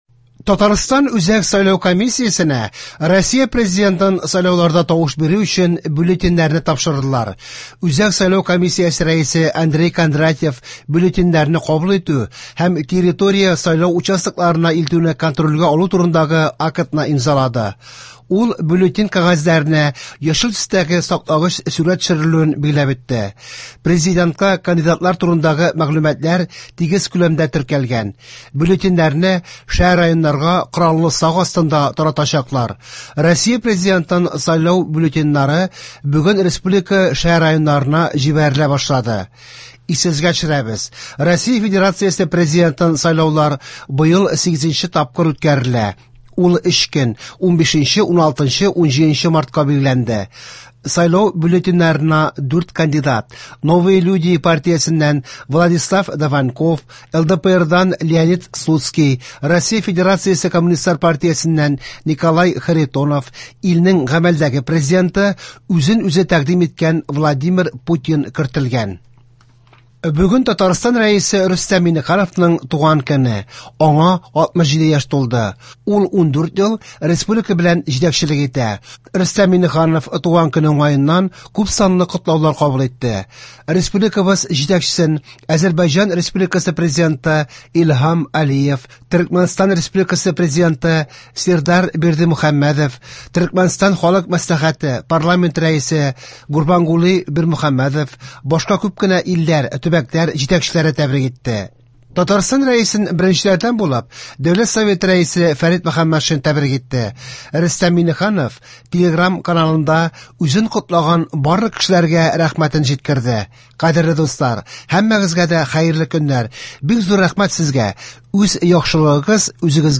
Яңалыклар (01. 03. 24)